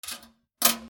/ E｜乗り物 / E-45 ｜自転車
自転車の鍵を置く 金属
『カチャ』